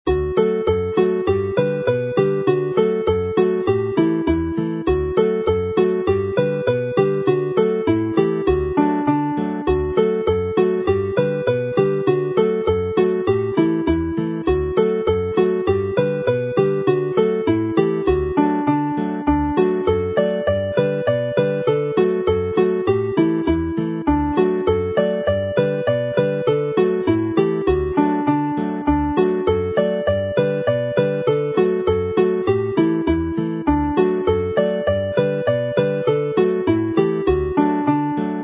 Alawon Cymreig - Set Ar Lan y Môr set - Welsh folk tunes